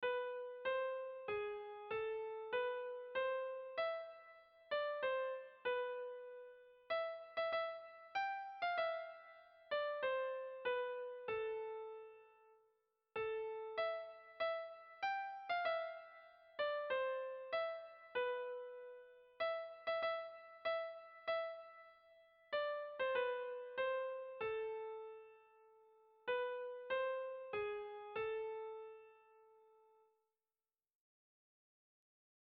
Sehaskakoa
Lau puntuko berdina, 10 silabaz